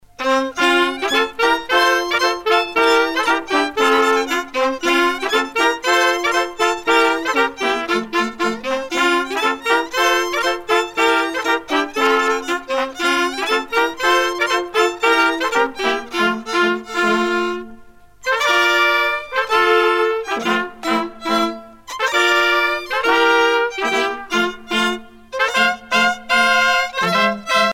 La Badoise, polka